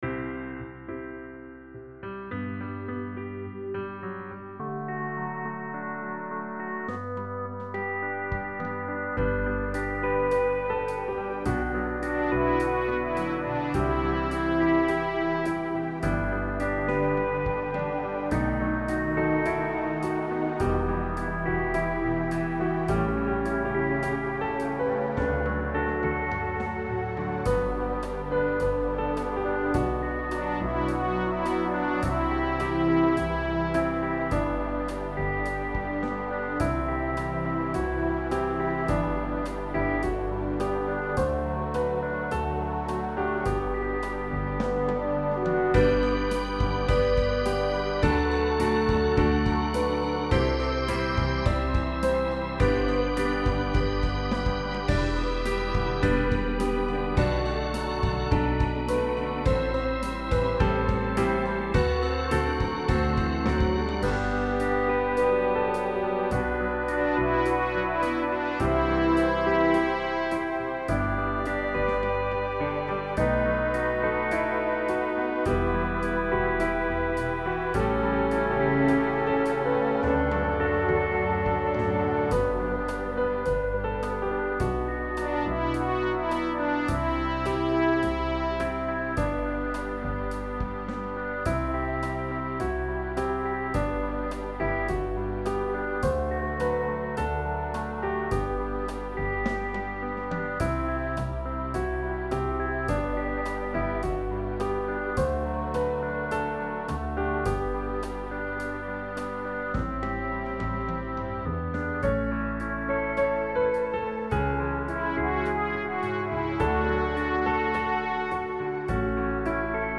MP3 instrumental